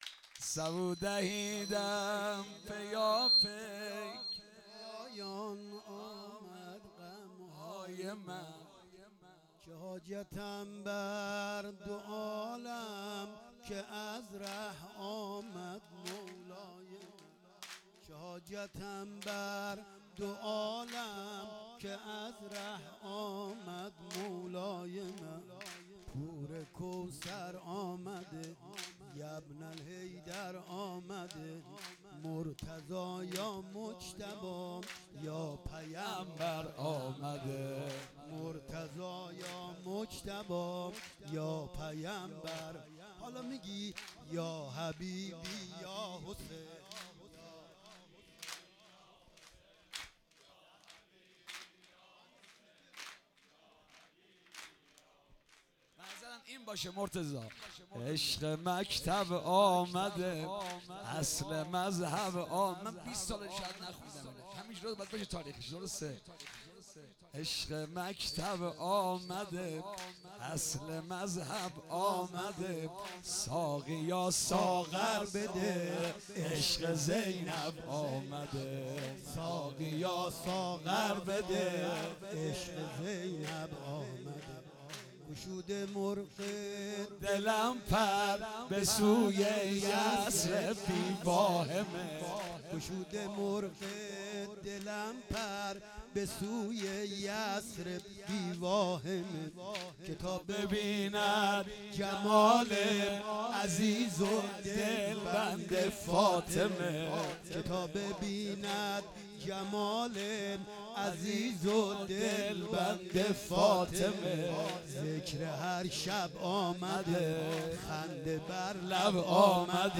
جشن ولادت امام سجاد(ع) ۹۸ همخوانی،سرود